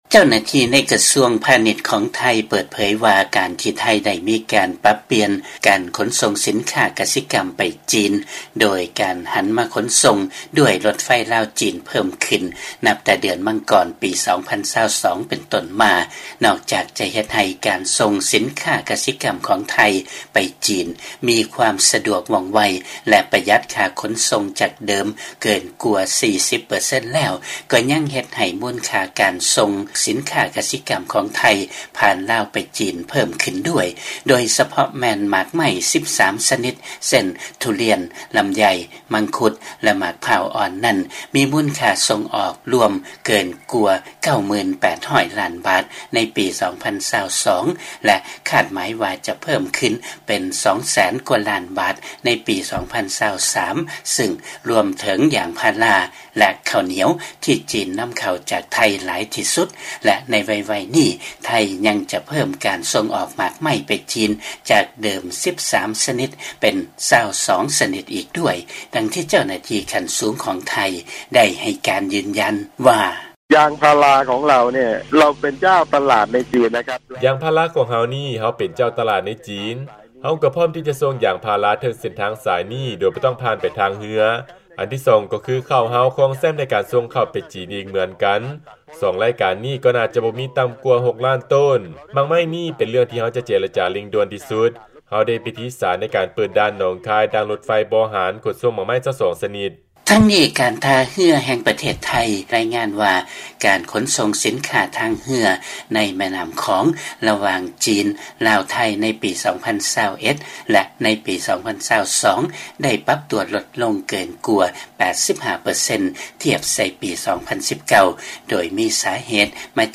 ຟັງລາຍງານ ໄທຈະສົ່ງອອກໝາກໄມ້ກວ່າ 2 ແສນລ້ານບາດຜ່ານລາວໄປ ຈີນ ໂດຍລົດໄຟ ລາວ-ຈີນ ໃນປີ 2023 ນີ້